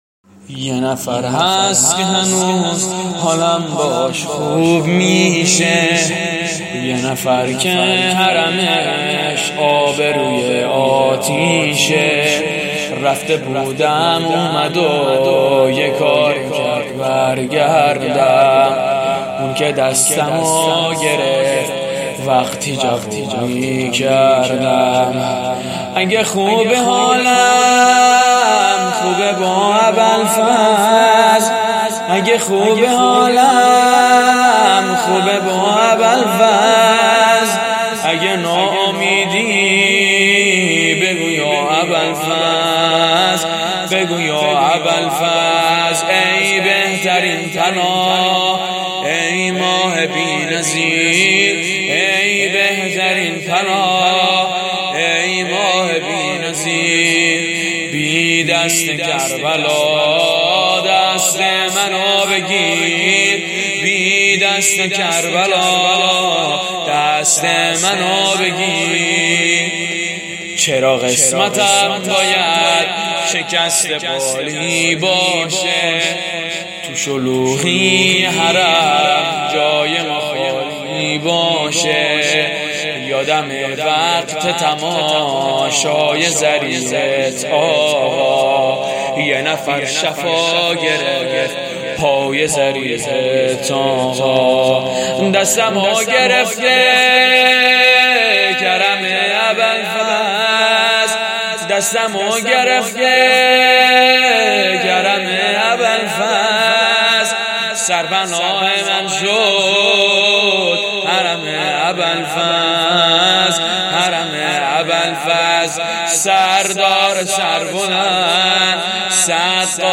شب نهم محرم الحرام ۹۹(حسینیه ریحانة الحسین (س)